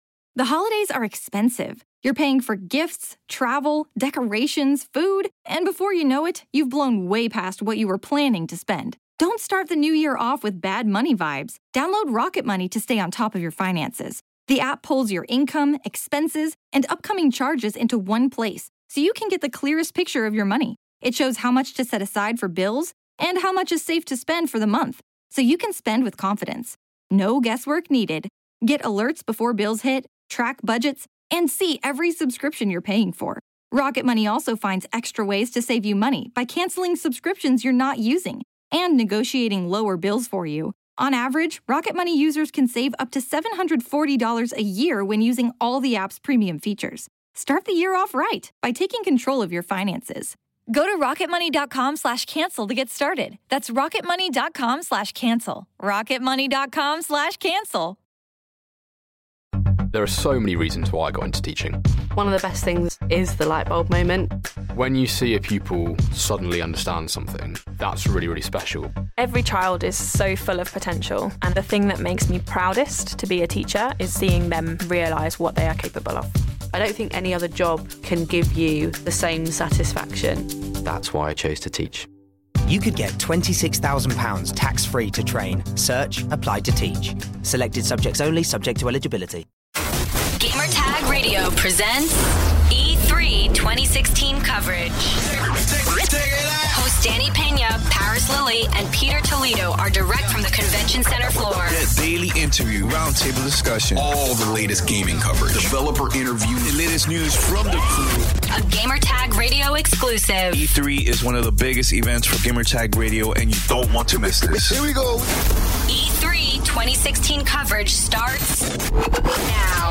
Roundtable discussion about the Xbox E3 2016 press conference featuring Major Nelson. Xbox Scorpio unveiled, Xbox Anywhere, Gwent standalone game, Tekken 7 & more!